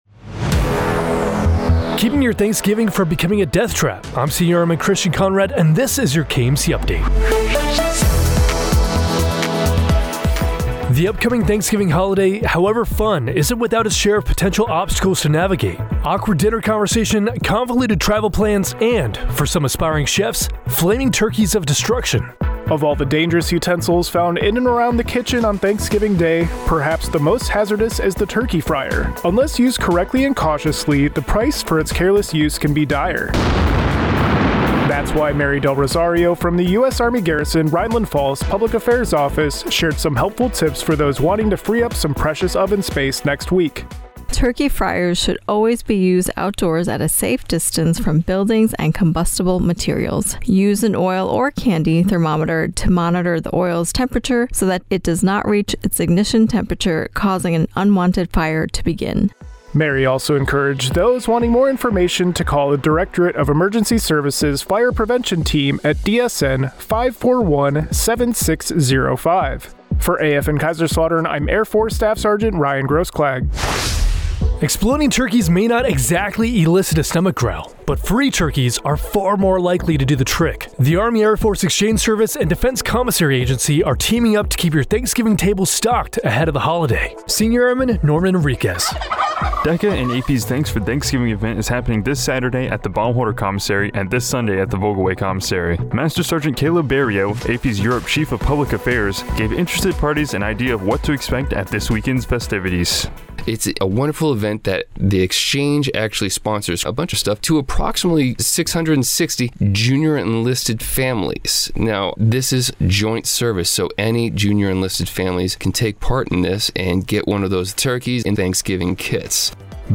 Defense Commissary Agency and Army and Air Force Exchange Service representatives also spoke with the station about their upcoming event, Thanks for Thanksgiving, taking place Nov. 16 and 17 during which they plan to give away Thanksgiving meal kits to 660 junior enlisted families.